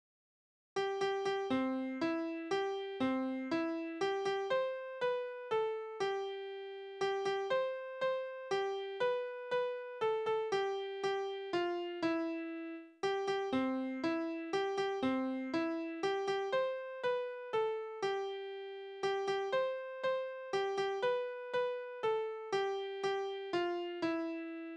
Kindertänze: Erbsen suchen
Tonart: C-Dur
Taktart: 3/4
Tonumfang: Oktave
Kommentar Einsender*in: Spiellied der Schulmädchen